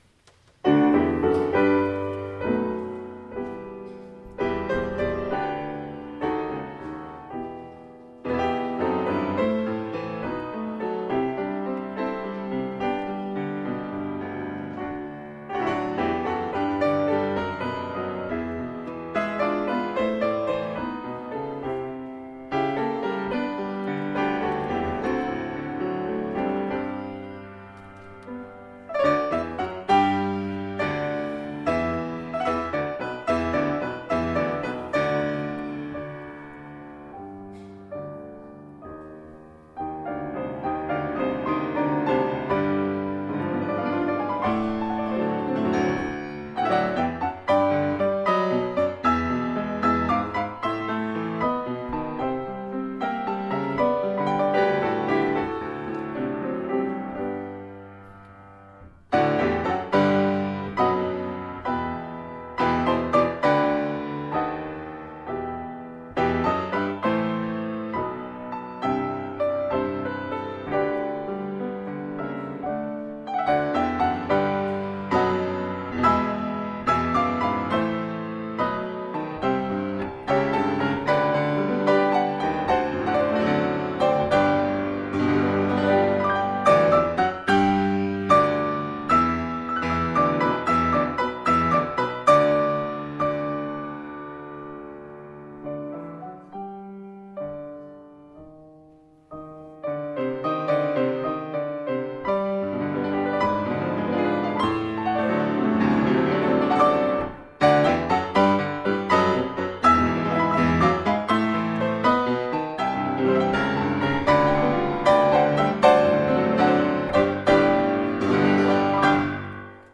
Even though this is done as a piano solo, I want to mention that the lyrics